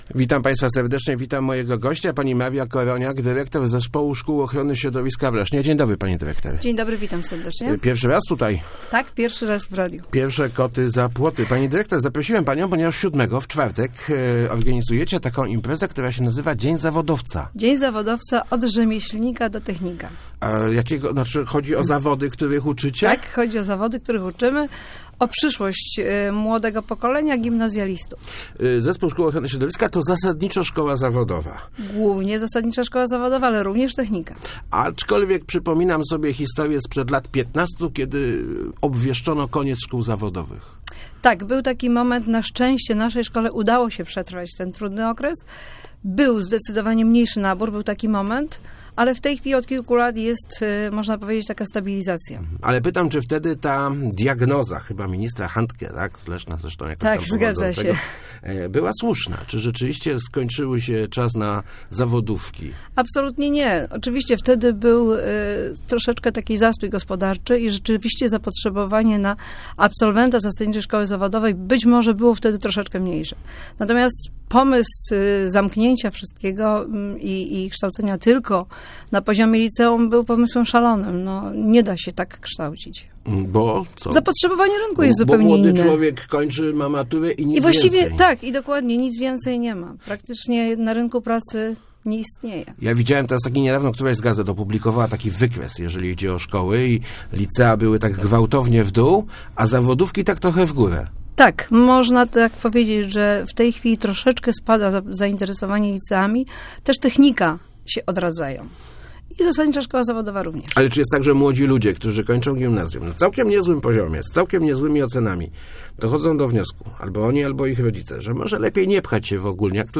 Start arrow Rozmowy Elki arrow Zawodówka daje perspektywy?